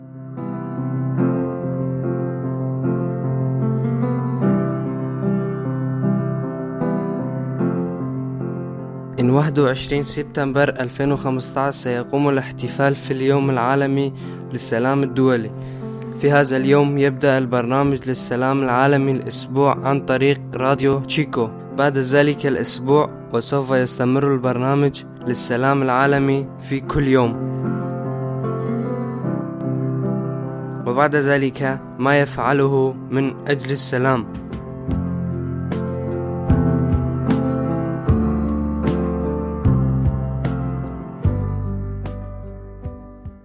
Jingle Weltfriedenswoche Arabisch
jingle arabisch.mp3